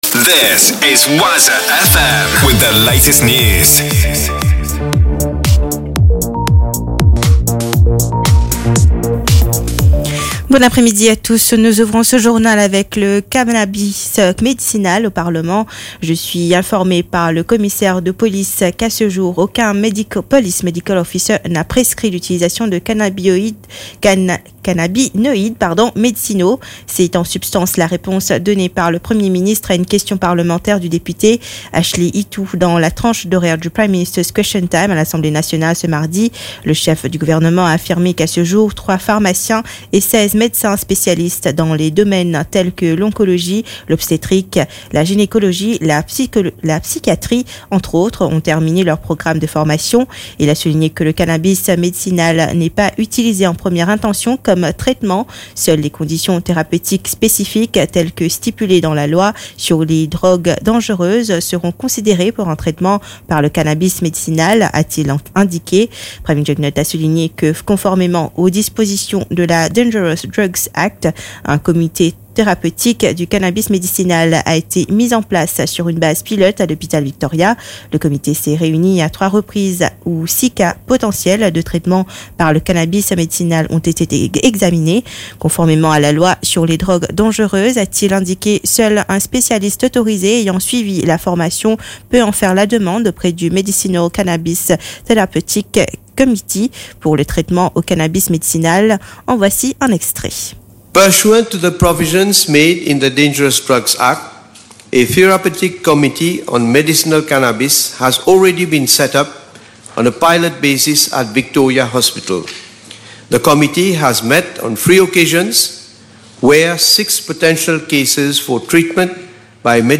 NEWS 16H - 5.12.23